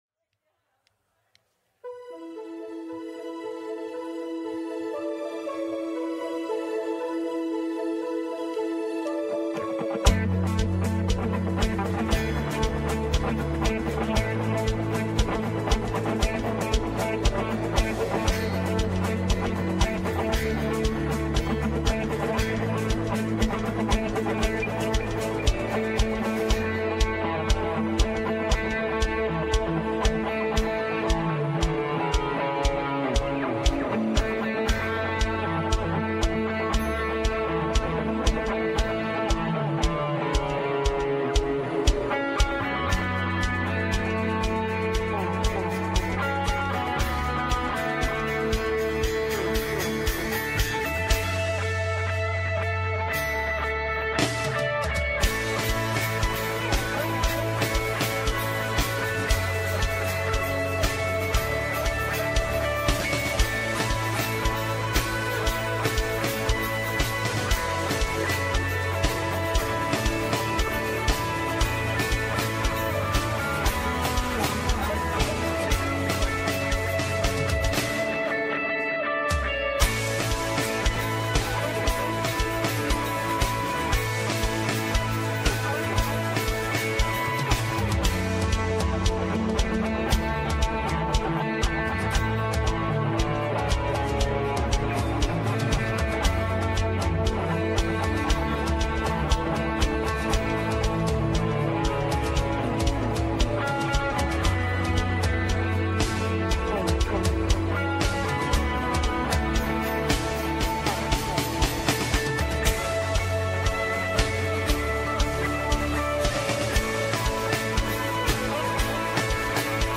Westgate Chapel Sermons Live Free: Freedom...